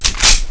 assets/pc/nzp/sounds/weapons/biatch/magin.wav at 29b8c66784c22f3ae8770e1e7e6b83291cf27485